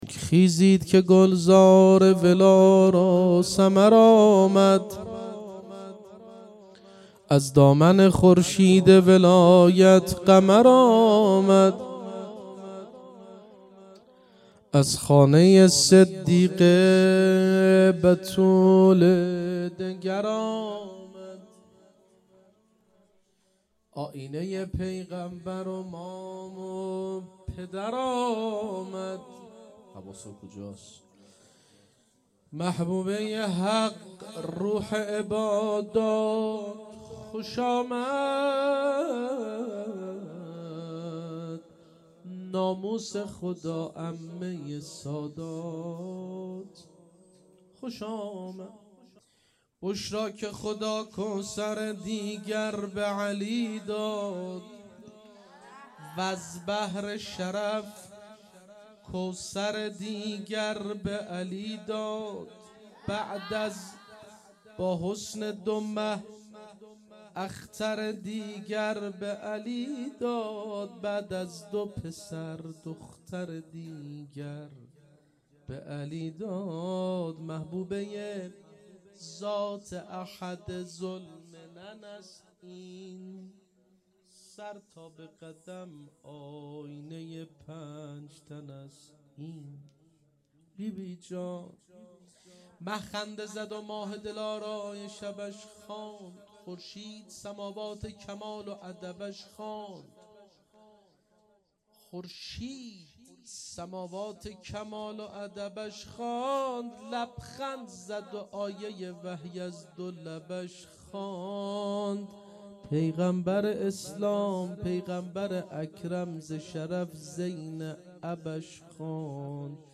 جشن ولادت حضرت زینب سلام الله علیها